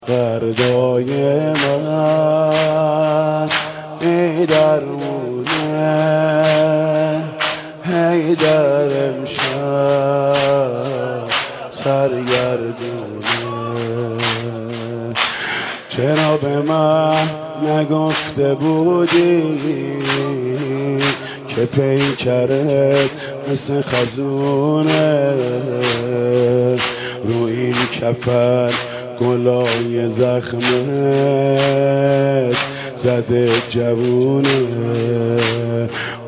زنگ موبایل به مناسبت ایام فاطمیه؛ «دردای من بی درمونه